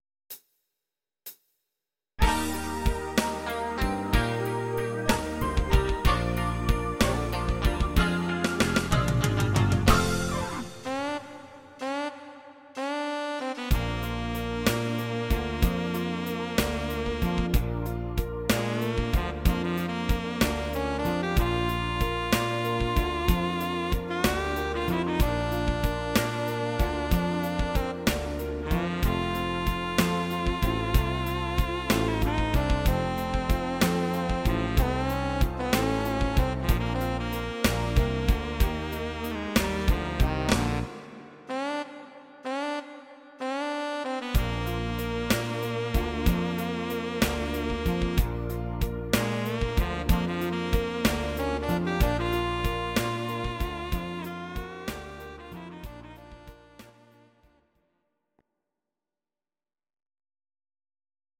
These are MP3 versions of our MIDI file catalogue.
Please note: no vocals and no karaoke included.
Saxophon